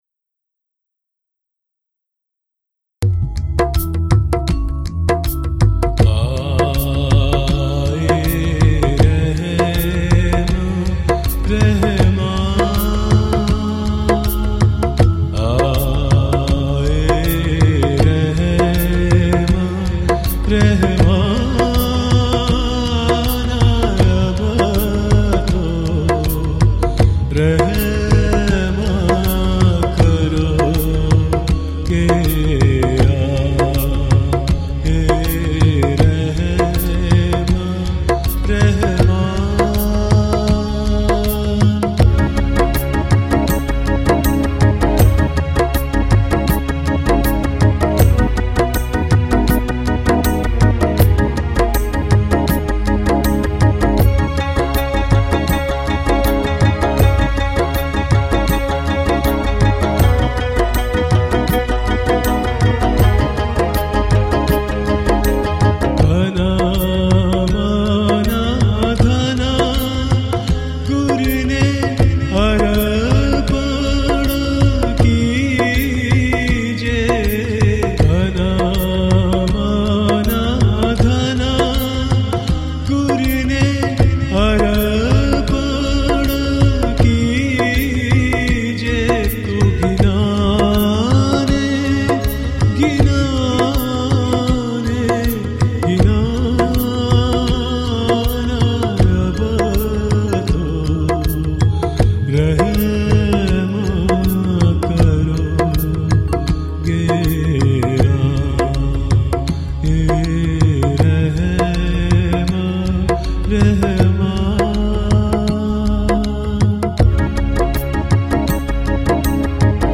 You may now download MP3 of devotional poetry